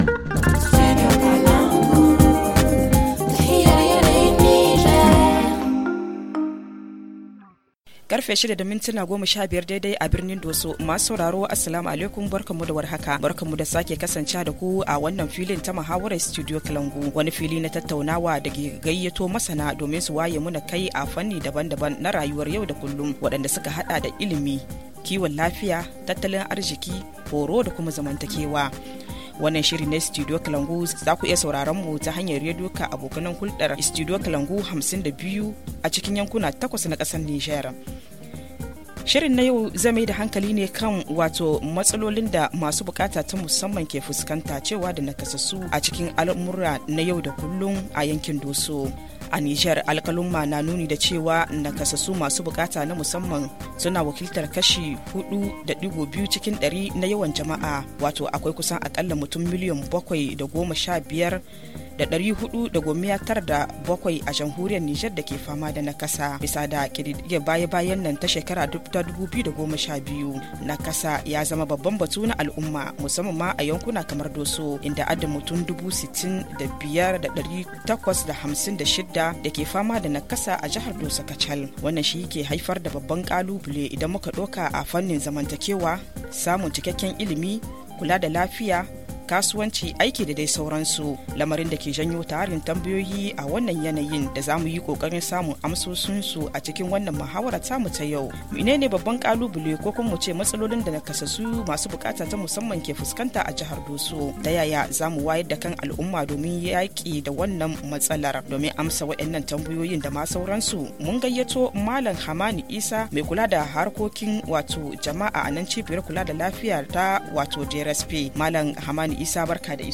Rediffusion : Quelle évolution pour les personnes en situation d’handicap à Dosso ?